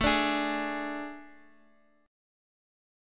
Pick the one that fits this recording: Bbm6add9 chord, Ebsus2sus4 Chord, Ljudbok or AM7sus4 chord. AM7sus4 chord